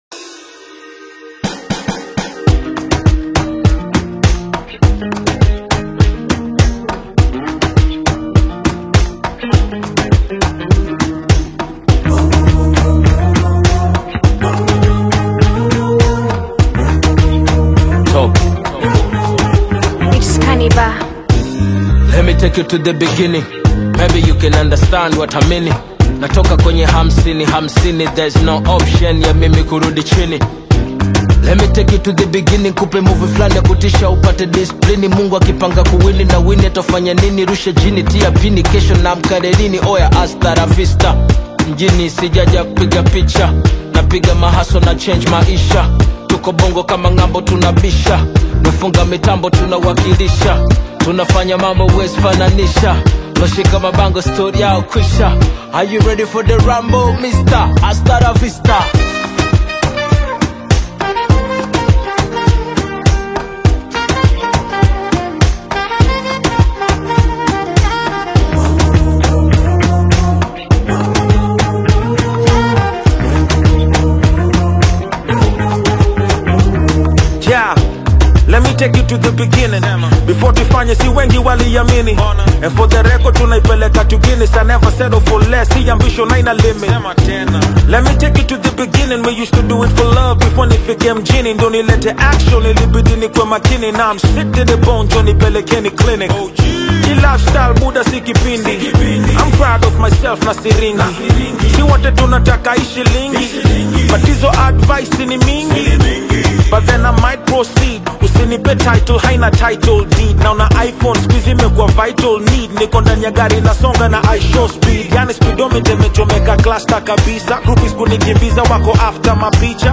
AUDIOKENYAN SONG